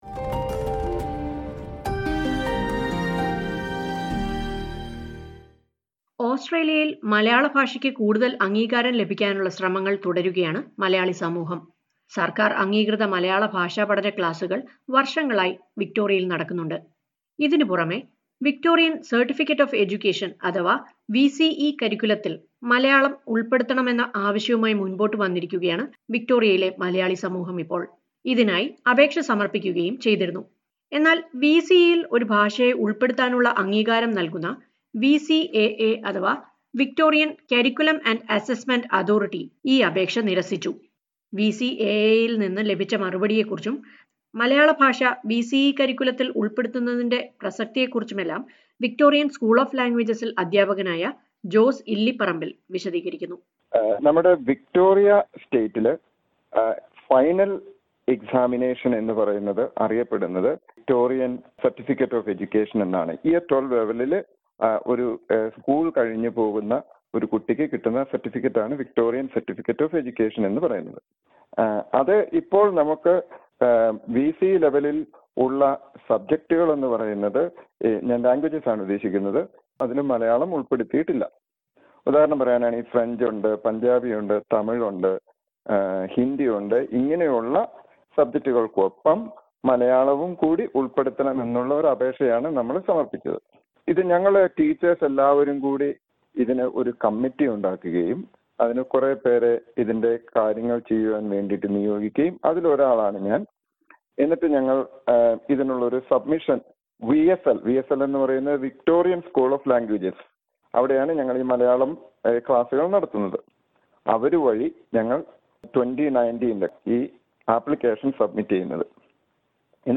As the application to include Malayalam in the Victorian curriculum has been rejected, the Malayalee community in Victoria has started an online petition. Listen to a report on the benefits of adding Malayalam to VCE and the community's efforts to include it.